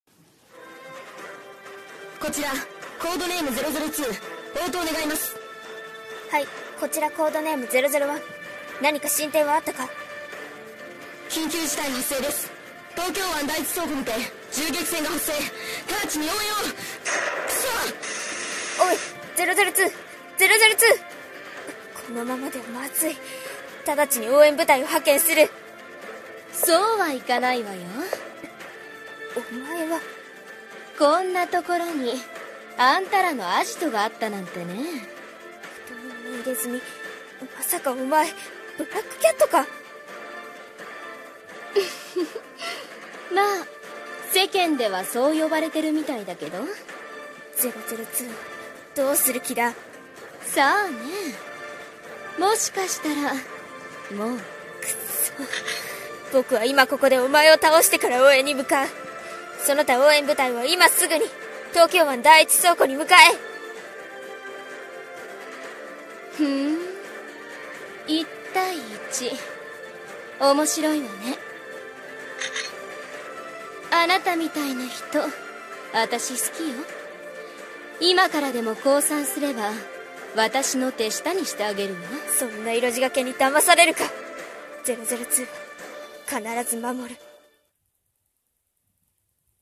【コラボ声劇】命懸けの戦い